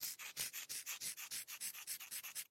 На этой странице вы найдете подборку звуков мела, пишущего по доске.
Звук рисования мелом